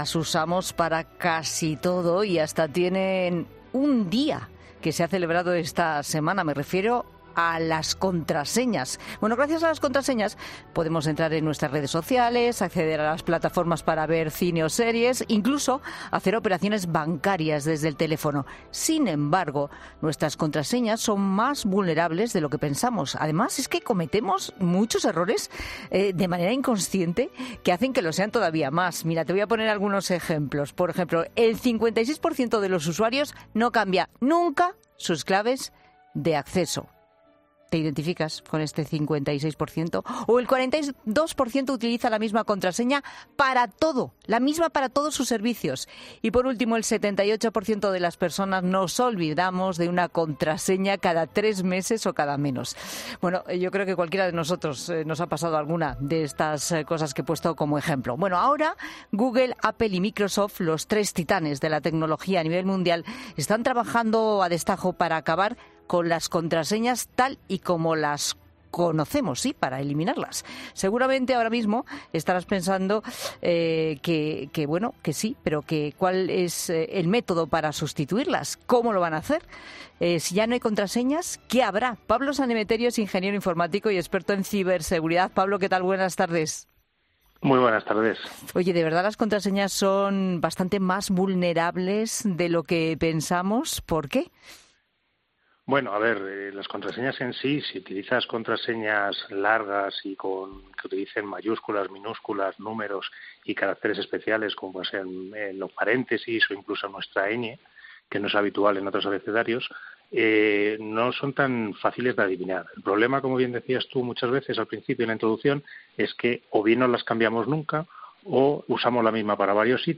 El experto analiza en 'La Tarde' cómo tener un código de acceso seguro y qué medidas hay que tomar ante los temidos hackers